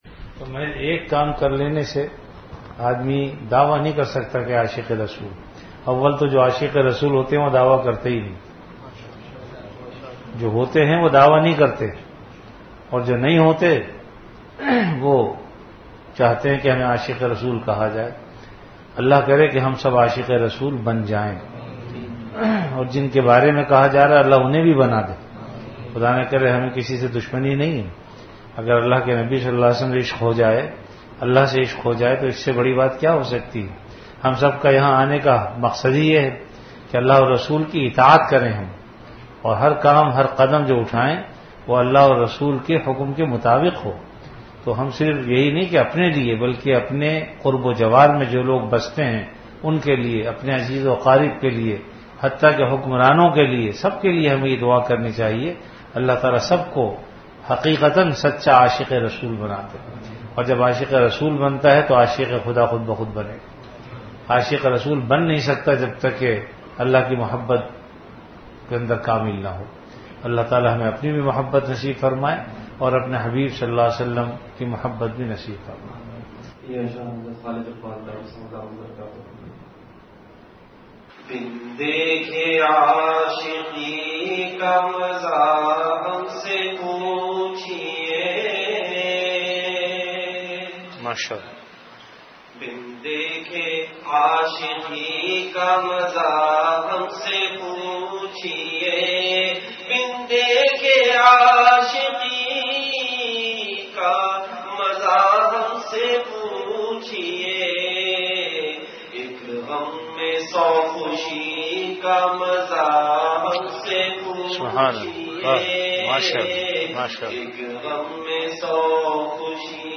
Majlis-e-Zikr
After Magrib Prayer